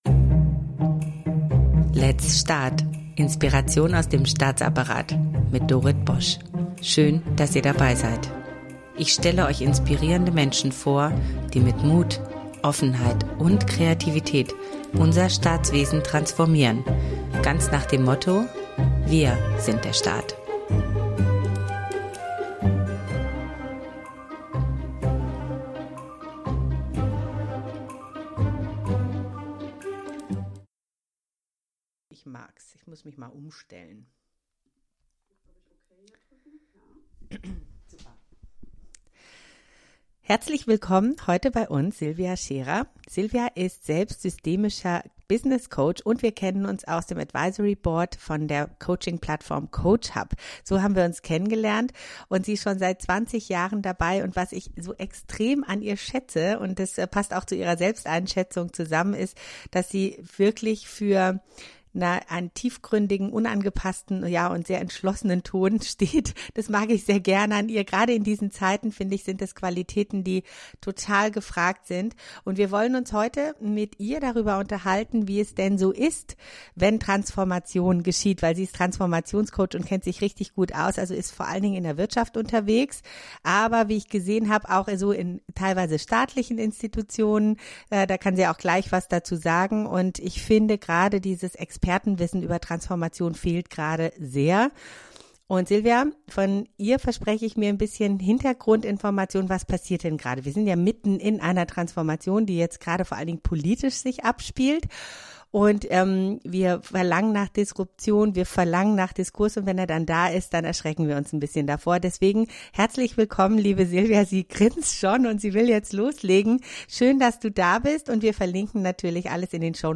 Ein Gespräch über Relevanz, Sinn und die Sehnsucht nach Emotion in Unternehmen.